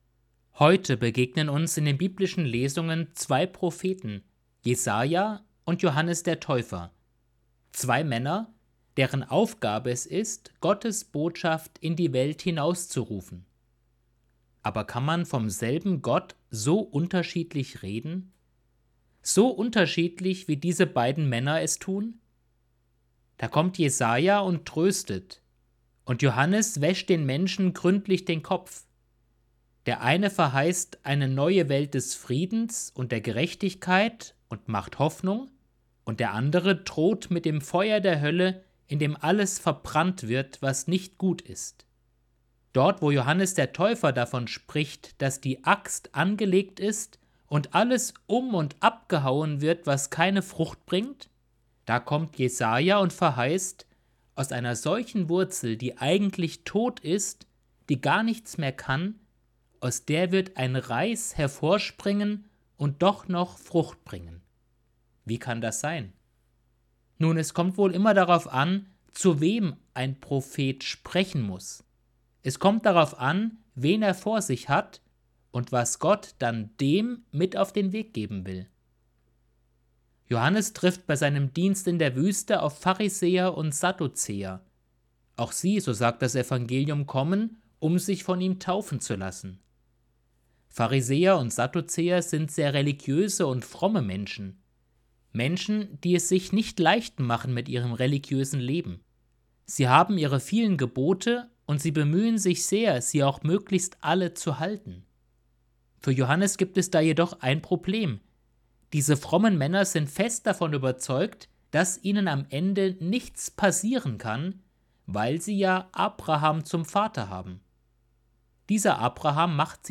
Predigt vom 29.01.2023